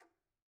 Tumba-Tap1_v1_rr2_Sum.wav